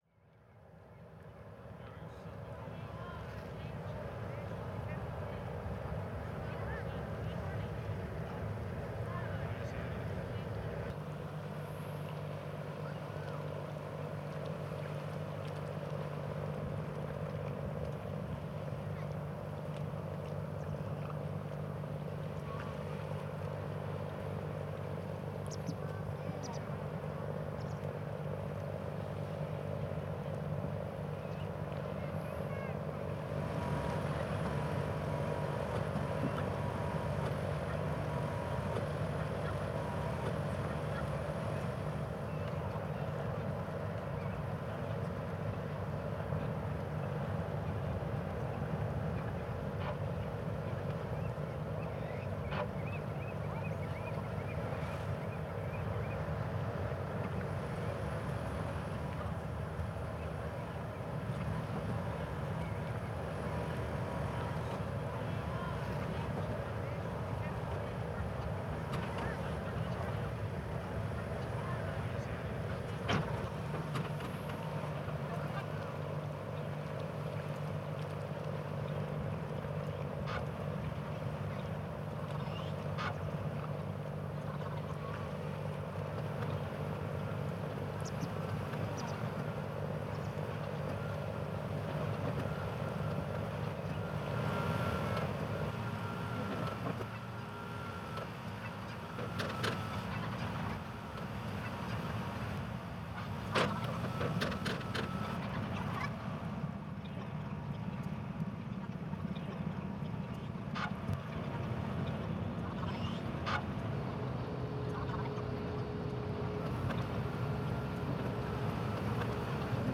Ferry at Uto reimagined